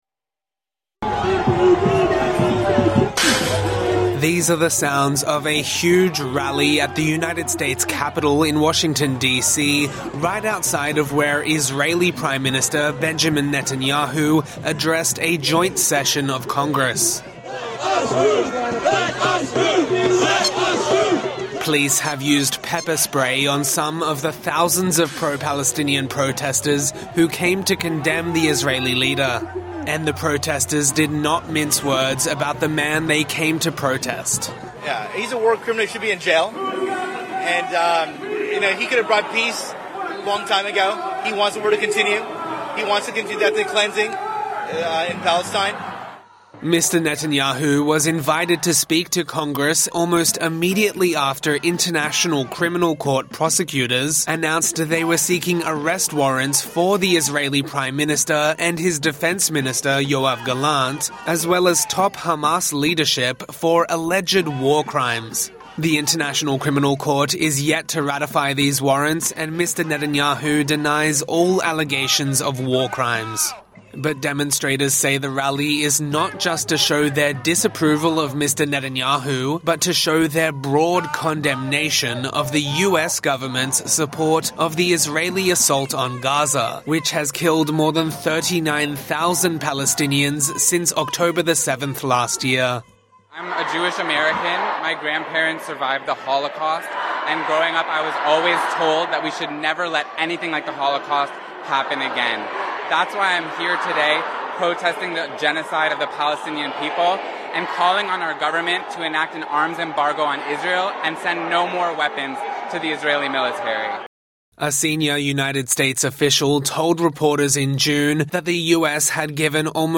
TRANSCRIPT These are the sounds of a huge rally at the United States Capitol in Washington, right outside of where Israeli Prime Minister Benjamin Netanyahu addressed a joint-session of Congress.